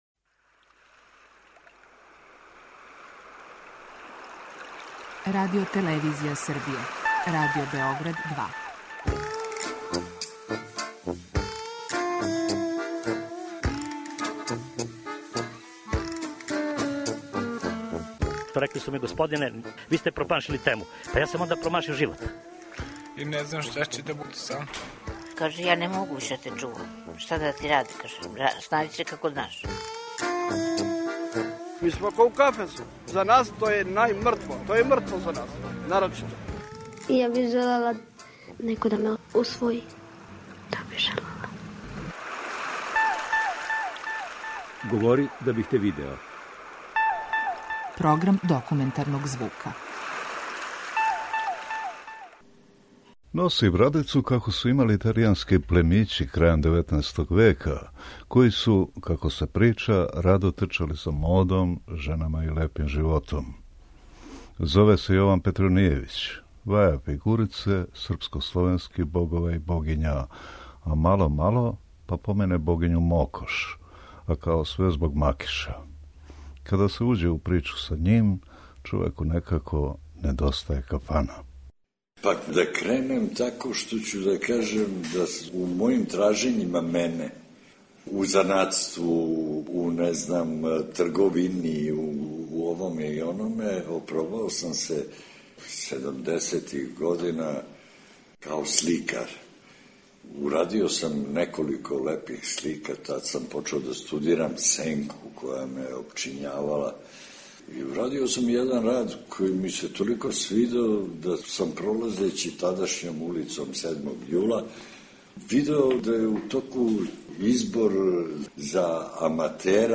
Документарни програм
преузми : 10.78 MB Говори да бих те видео Autor: Група аутора Серија полусатних документарних репортажа, за чији је скупни назив узета позната Сократова изрека: "Говори да бих те видео". Ова оригинална продукција Радио Београда 2 сједињује квалитете актуелног друштвеног ангажмана и култивисане радиофонске обраде.